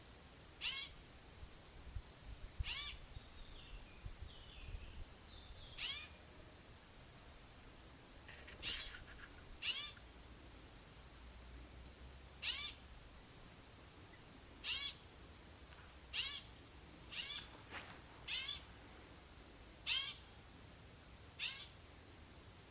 Cacicus chrysopterus (Vigors, 1825)
Nome em Inglês: Golden-winged Cacique
Local: RPPN Corredeiras do Rio Itajaí - Itaiópolis - Santa Catarina
Canto